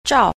a. 照 – zhào – chiếu